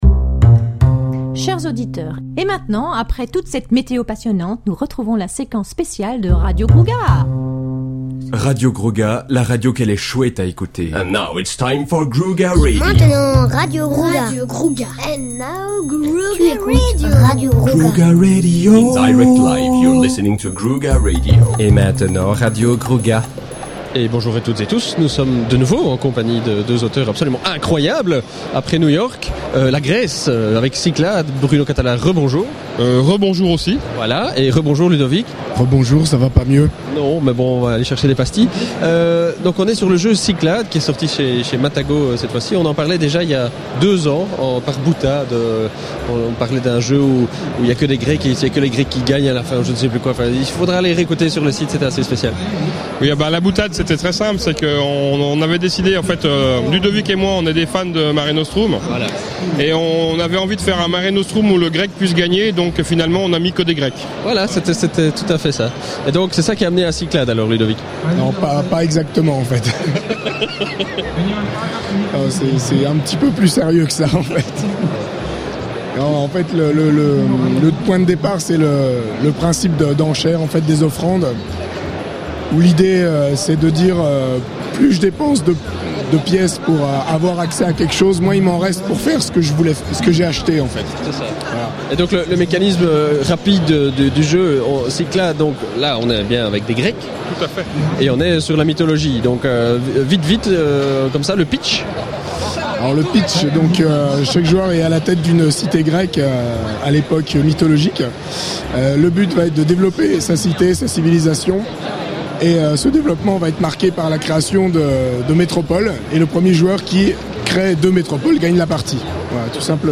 enregistré lors du salon international du jeu de société Spiel 2009 à Essen/Allemagne